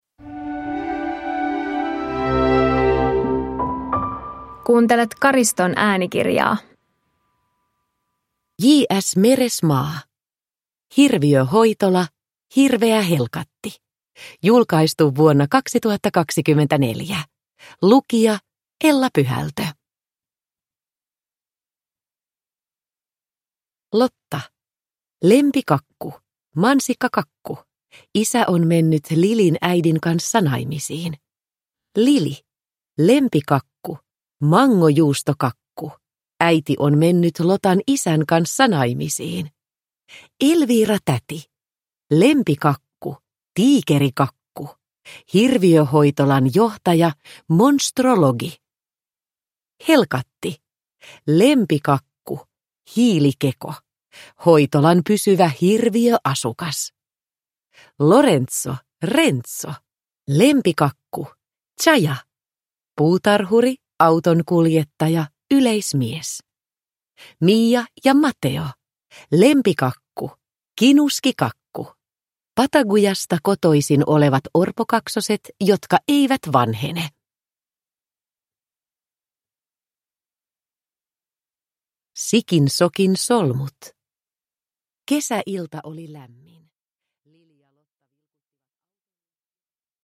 Hirviöhoitola - Hirveä helkatti – Ljudbok